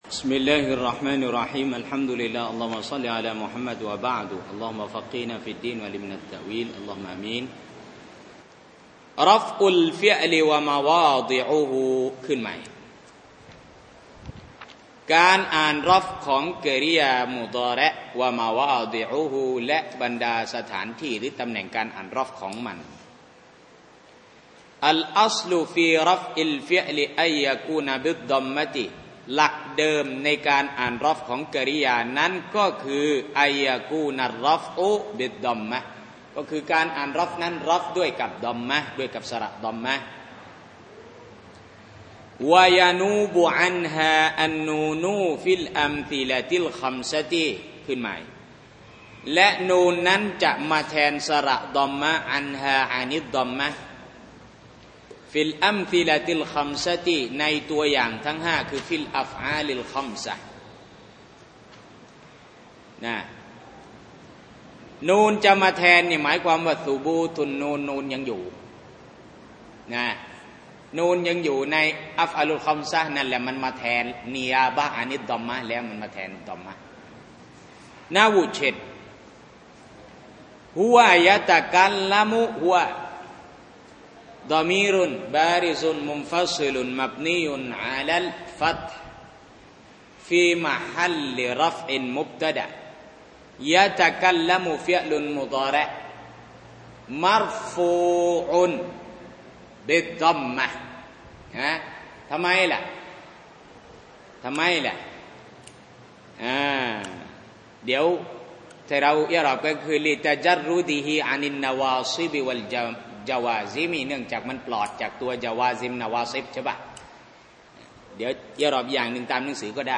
สอนที่โรงเรียนมัจลิซุดีนี ดาวน์โหลด